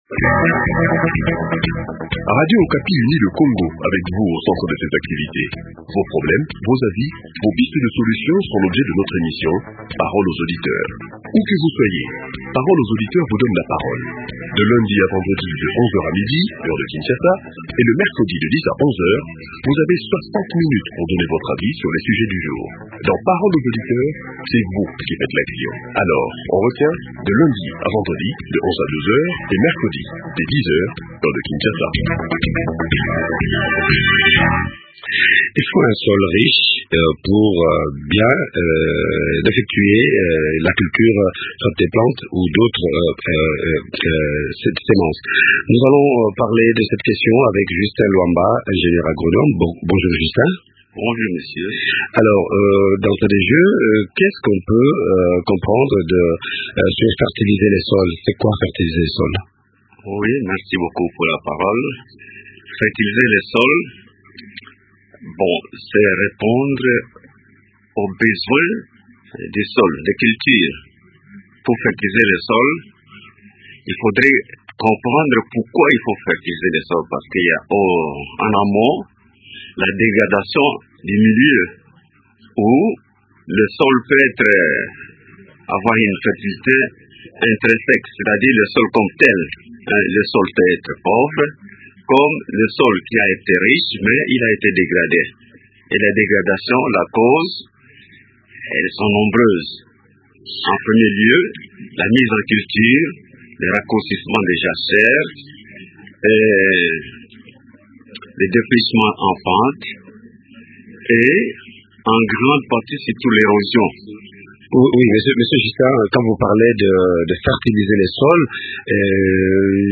Quelles sont alors les techniques appropriées pour fertiliser le sol avant de cultiver ? Eléments de réponse dans cet entretien